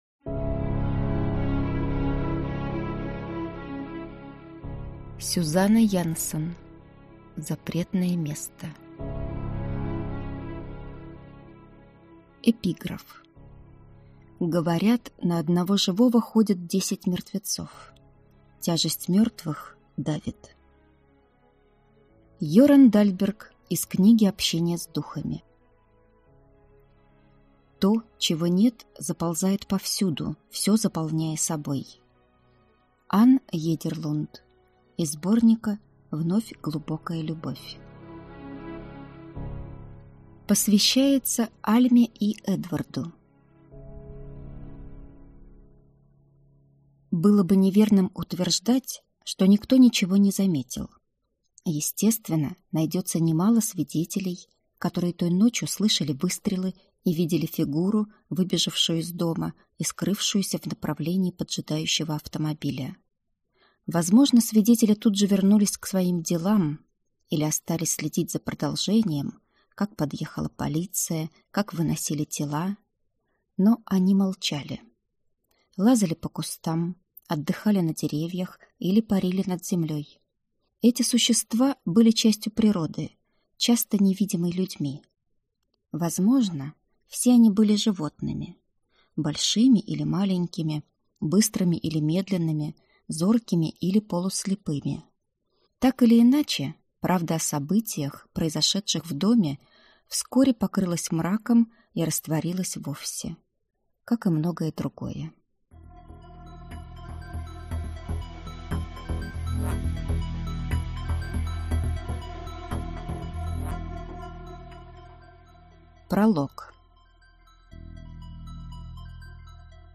Аудиокнига Запретное место | Библиотека аудиокниг